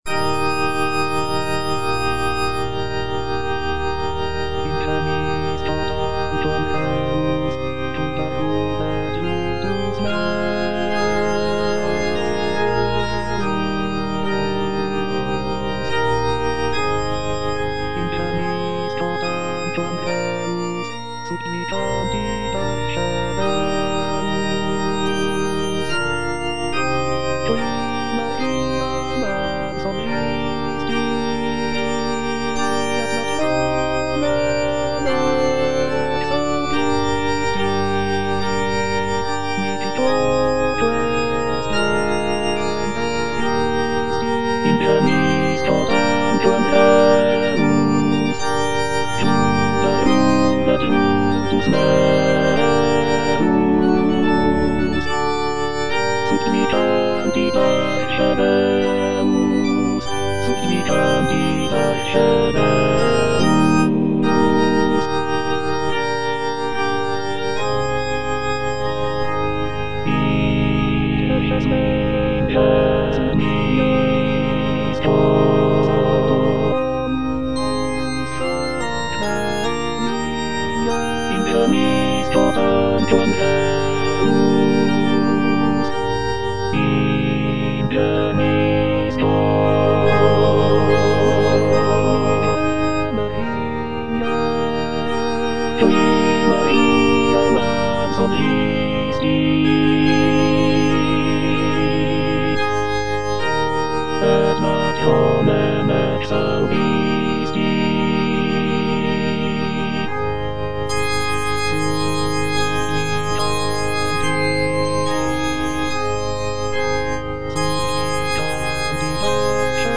All voices
is a sacred choral work rooted in his Christian faith.